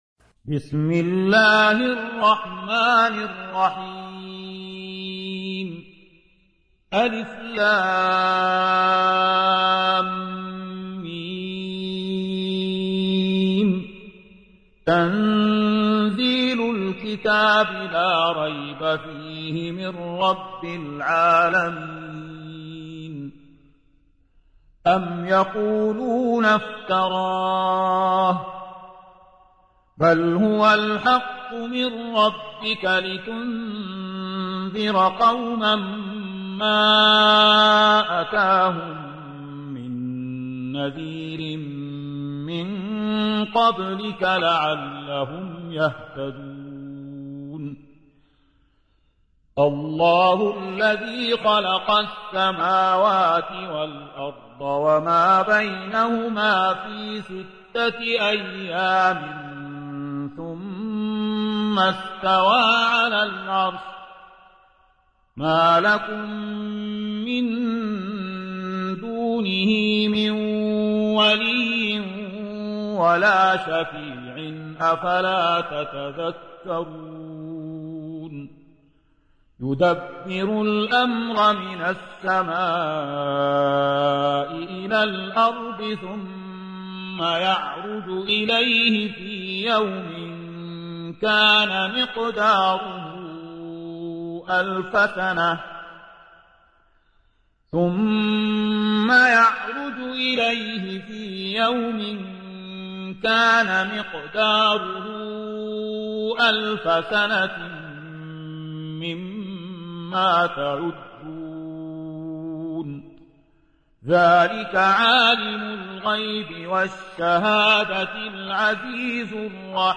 تحميل : 32. سورة السجدة / القارئ زكي داغستاني / القرآن الكريم / موقع يا حسين